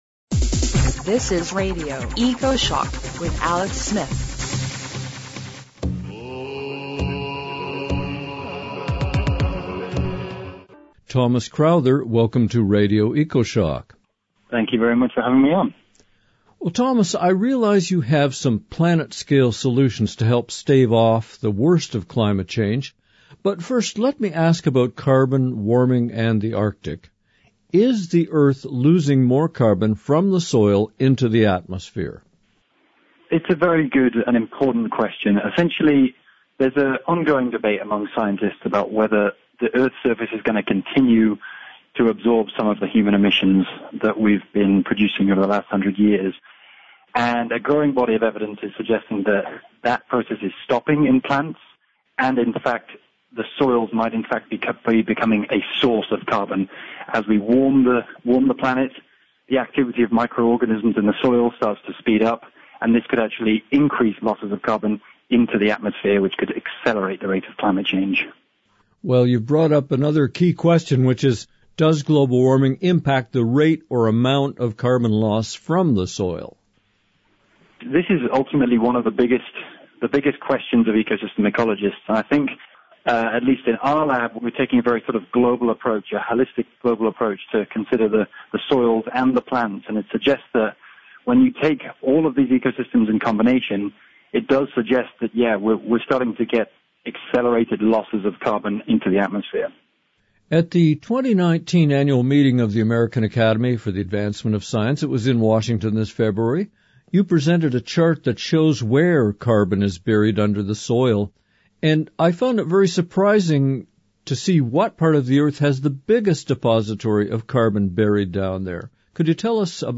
Our last interview on Radio Ecoshock was in December 2016, and he’s been busy since then.